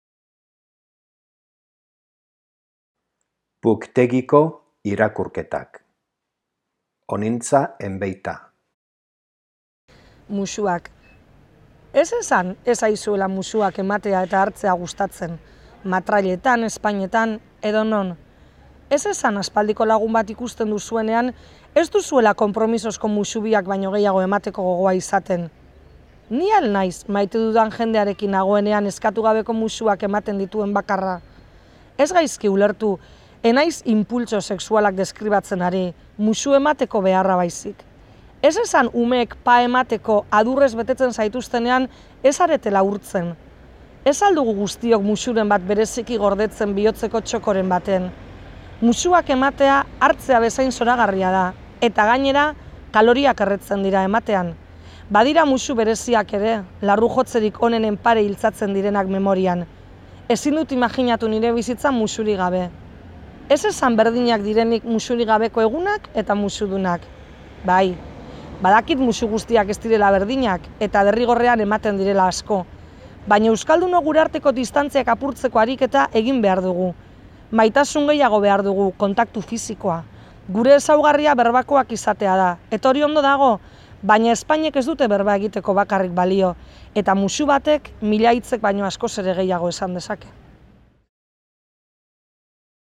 liburutik irakurri digu.